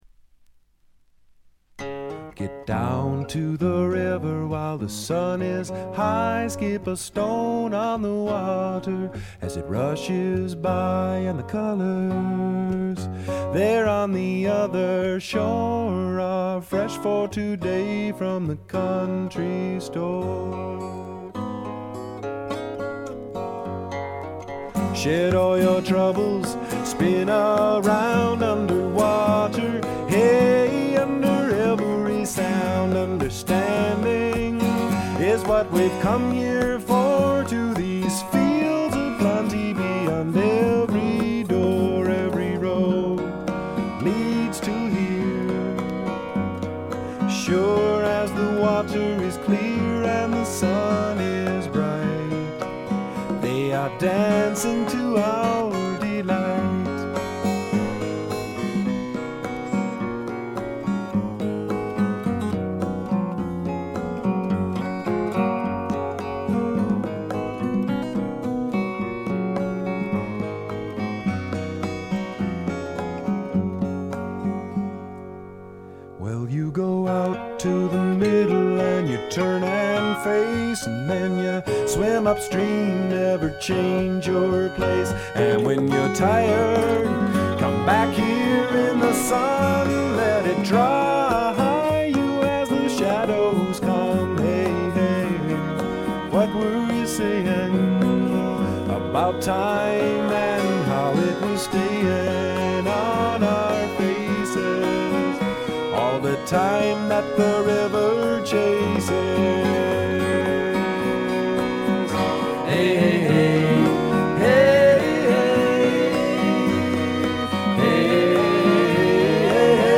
微細なチリプチわずか。
全体に静謐で、ジャケットのようにほの暗いモノクロームな世界。
試聴曲は現品からの取り込み音源です。